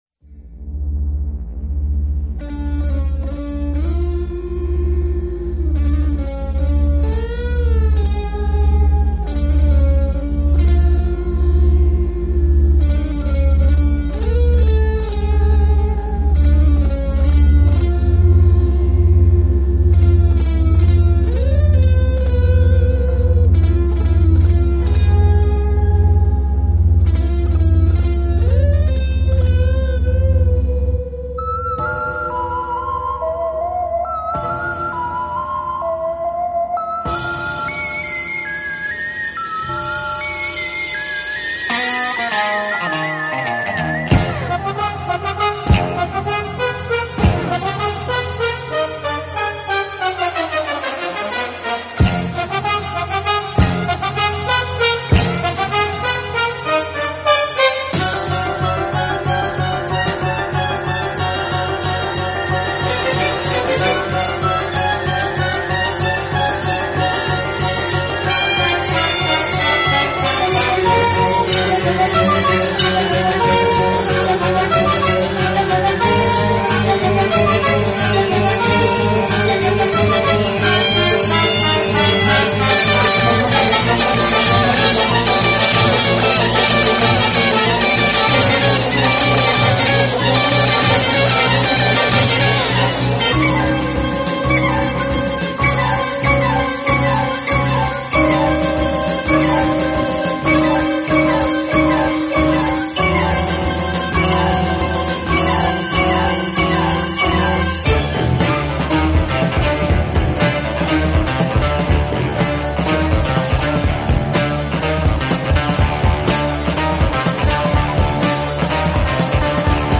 4. Soft strings doing "So long Judas. Poor old Judas"